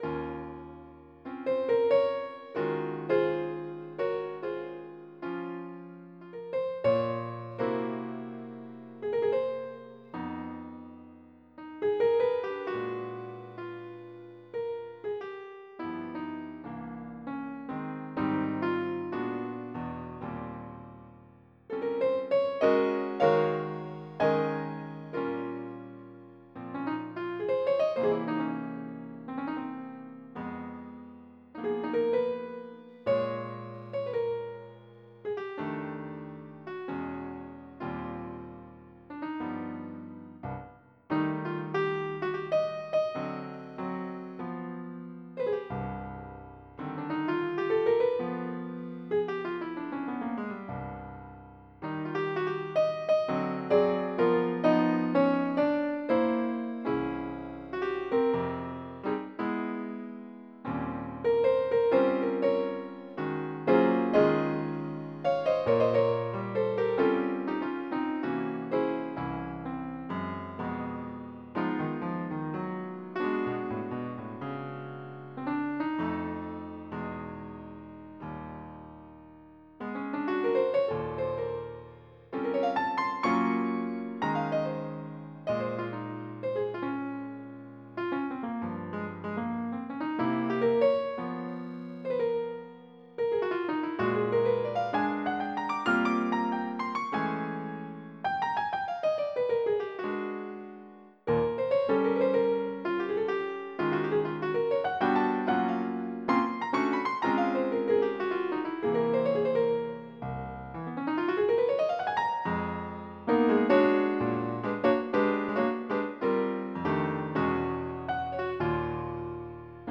MIDI Music File
JAZZ31.mp3